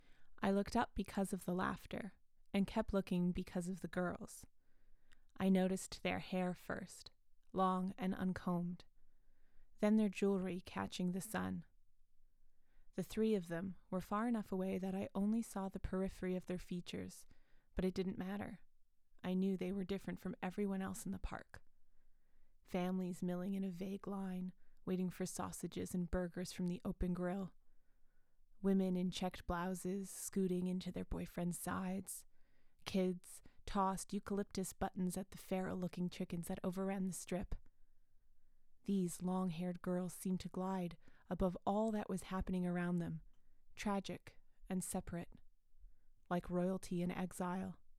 Extrait livre audio - ANG